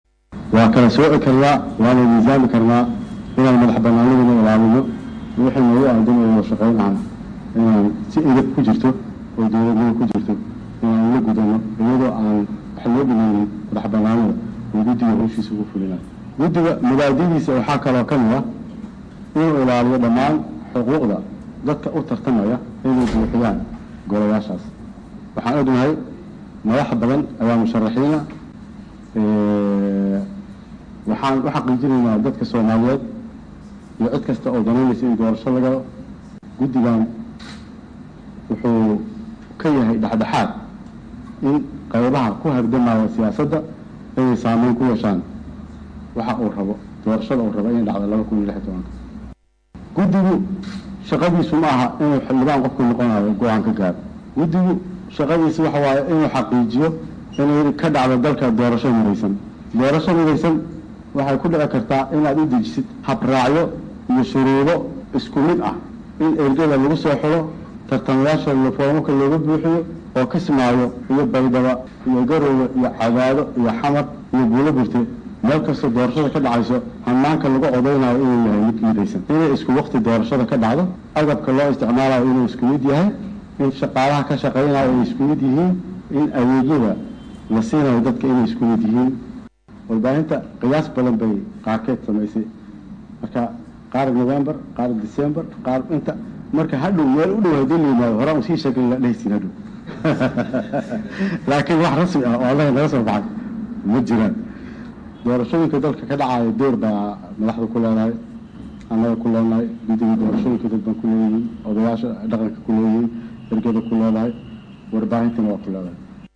Guddoomiyaha Guddiga Hirgelinta doorashooyinka ee heer Federaal Cumar Maxamed Cabdulle Dhagey oo shir jaraa’id ku qabtay magaalada Muqdisho ayaa faah faahin ka bixiyay qorshayaasha hor-yaala guddigooda.